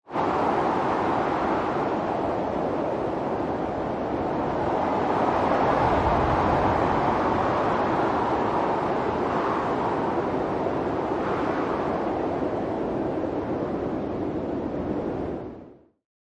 Snowstorm Blow
Snowstorm Blow is a free nature sound effect available for download in MP3 format.
Snowstorm Blow.mp3